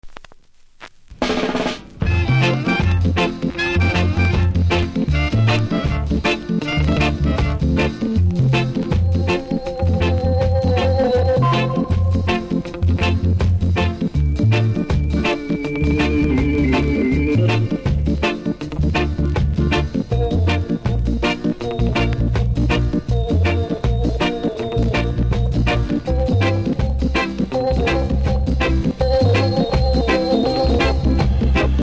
盤に少し歪み、曇り、センターずれありますがプレイは問題無いレベル。
キズも少なめノイズもなく良好なので試聴で下さい。